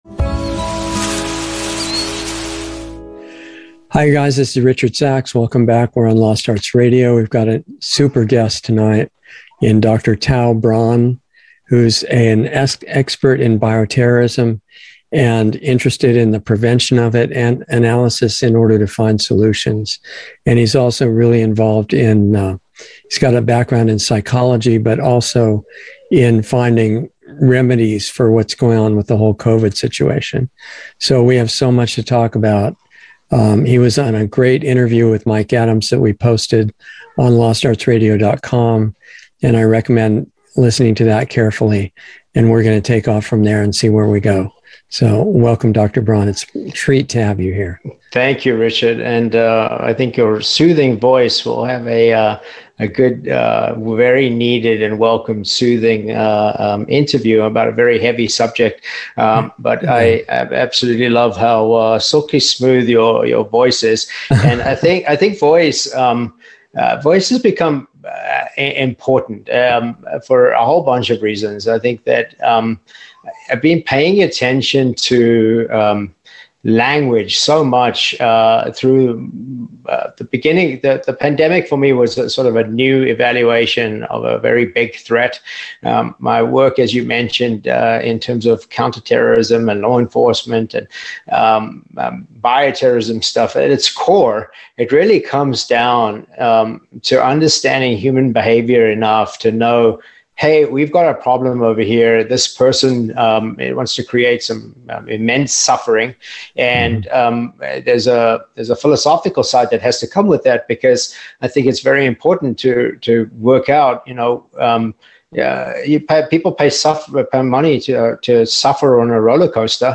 Lost Arts Radio Show on Sunday 7/10/22